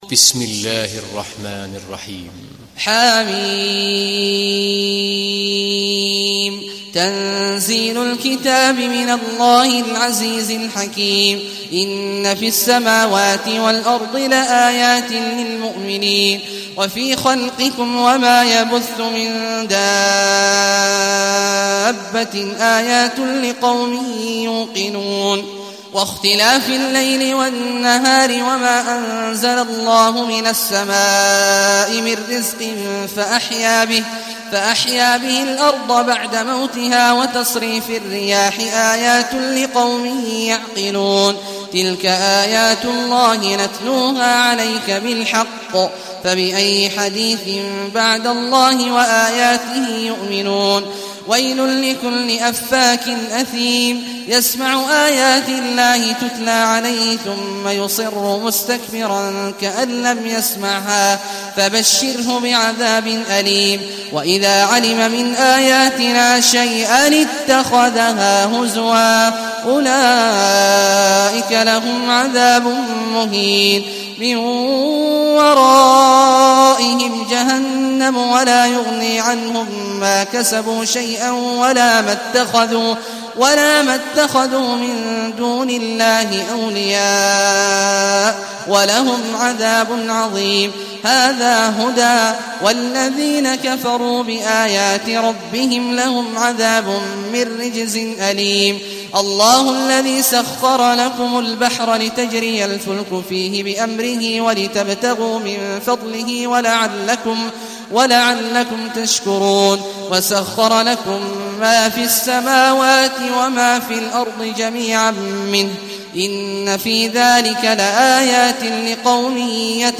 Casiye Suresi İndir mp3 Abdullah Awad Al Juhani Riwayat Hafs an Asim, Kurani indirin ve mp3 tam doğrudan bağlantılar dinle